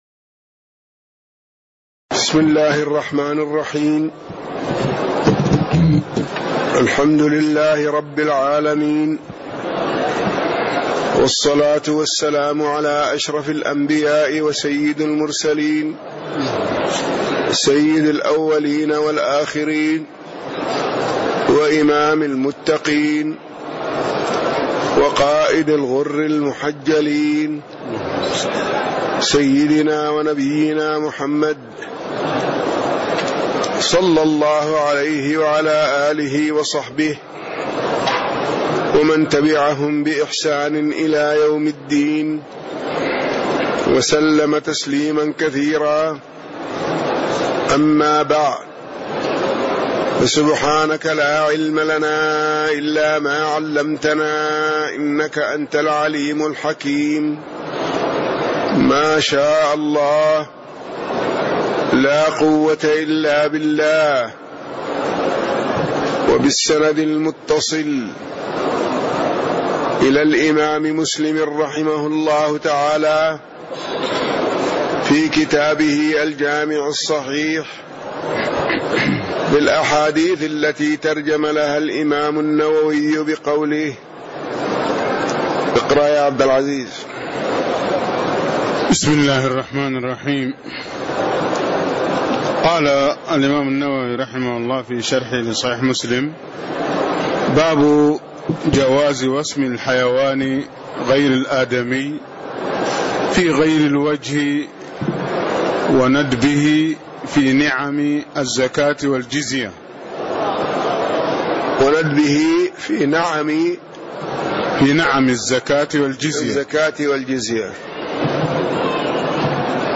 تاريخ النشر ٢٦ شوال ١٤٣٦ هـ المكان: المسجد النبوي الشيخ